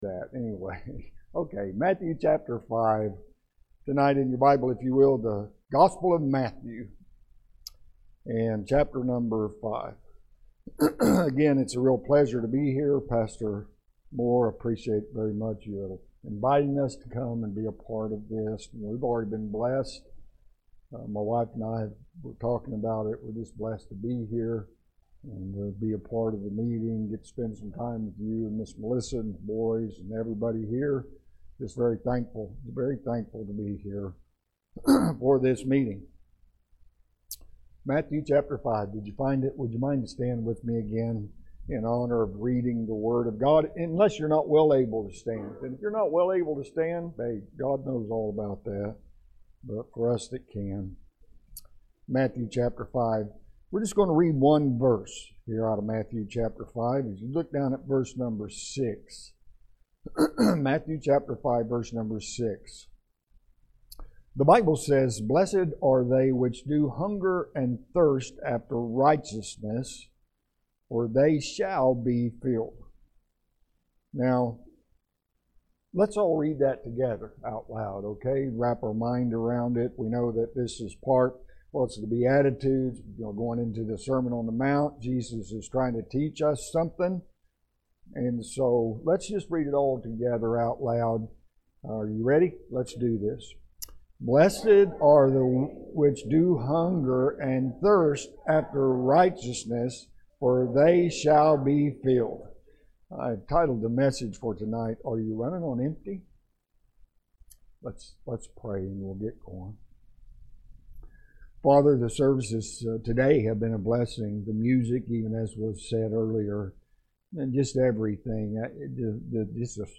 Matthew 5:6 – SPM – Spring Revival